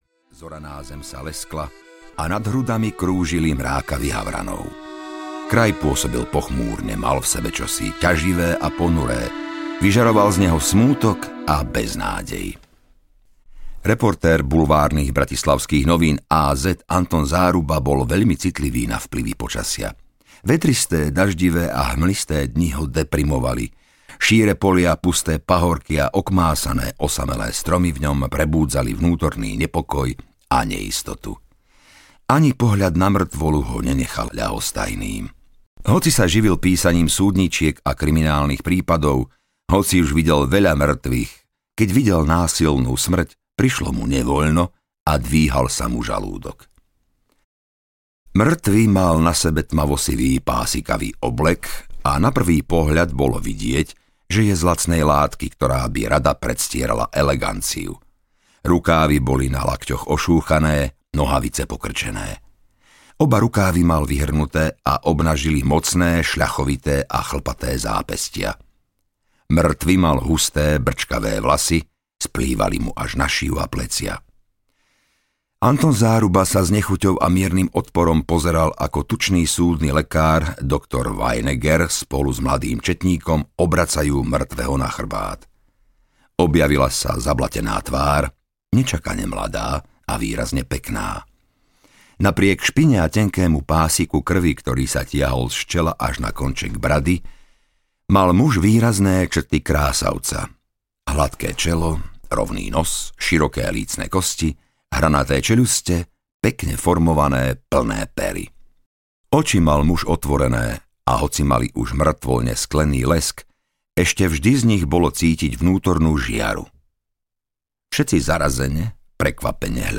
Usmej sa, si na rade audiokniha
Ukázka z knihy